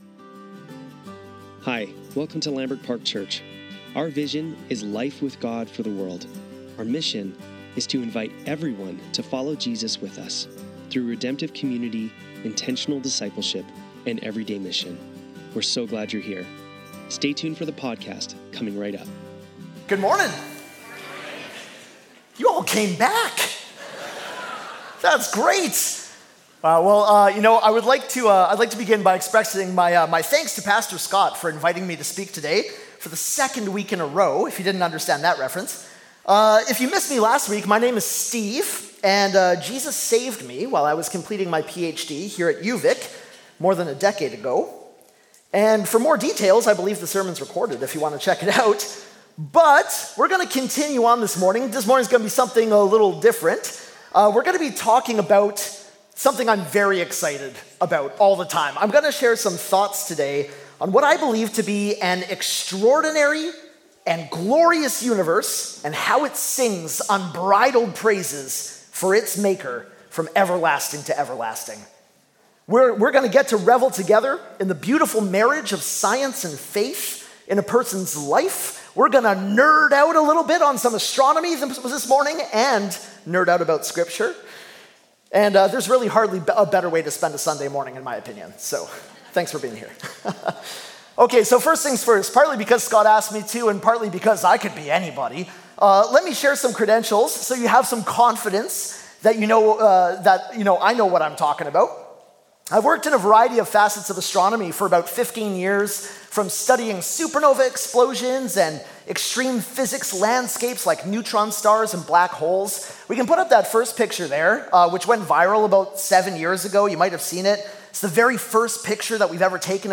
Sunday Service - January 18, 2026